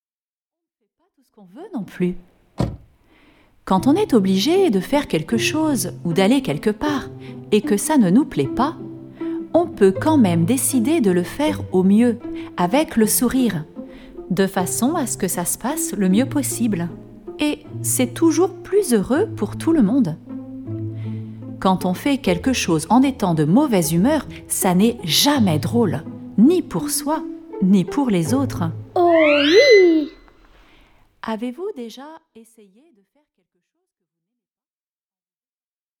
Mini-catéchèse